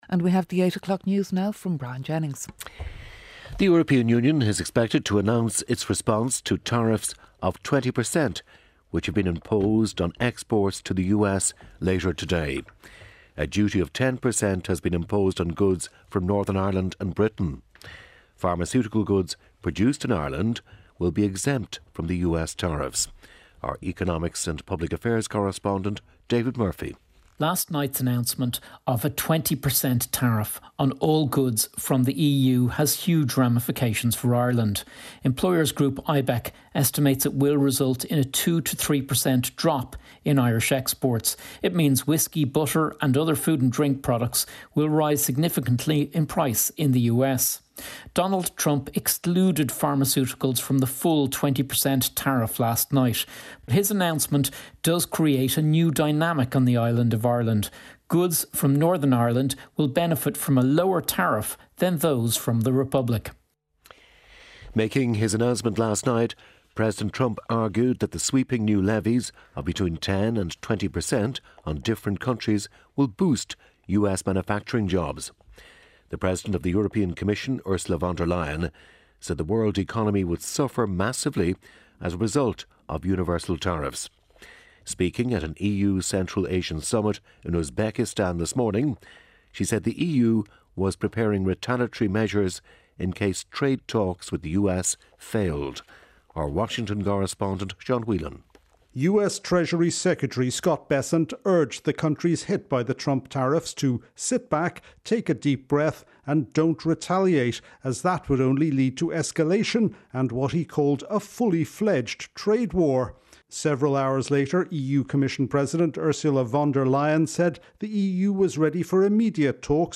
… continue reading 8600 επεισόδια # Daily News # Ireland # RTÉ # RTÉ Radio 1 # Business News # International News # News